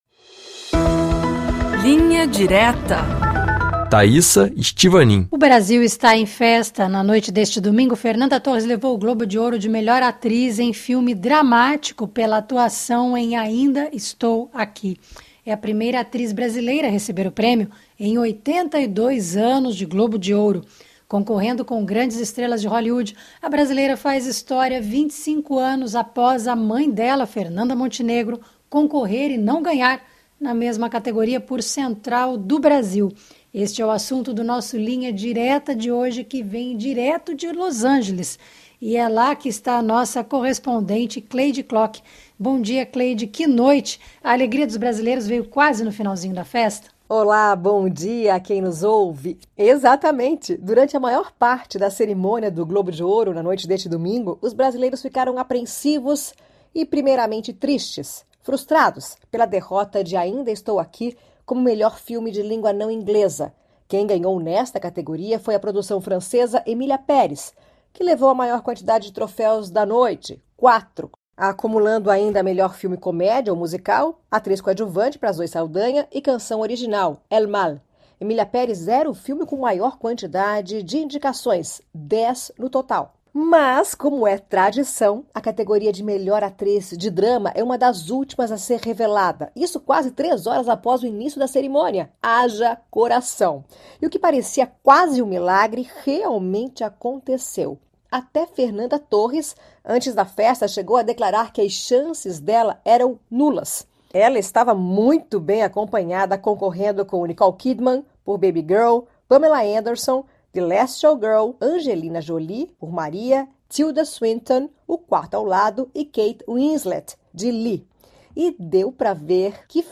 Bate-papo com os correspondentes da RFI Brasil pelo mundo para analisar, com uma abordagem mais profunda, os principais assuntos da atualidade.